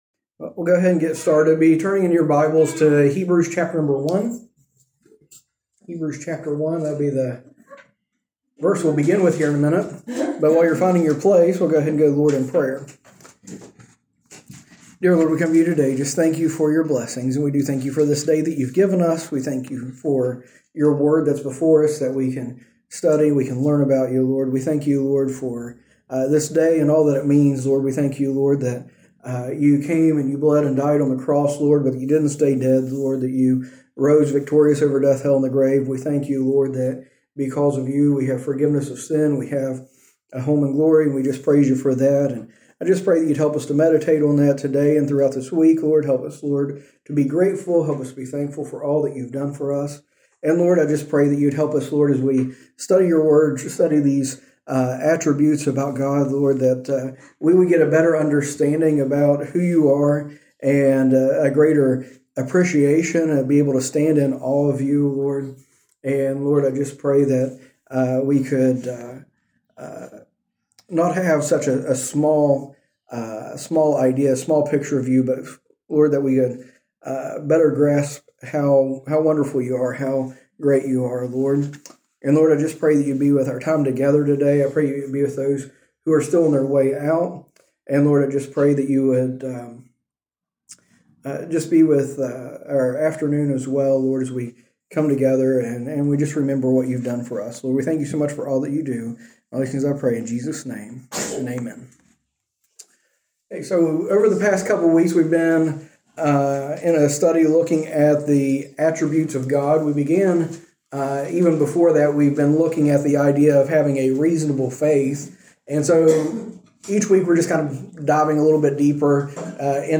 A message from the series "A Reasonable Faith."